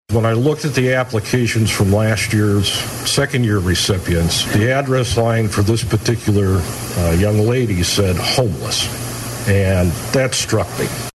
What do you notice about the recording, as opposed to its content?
spoke this week to the Coldwater Noon Rotary Club.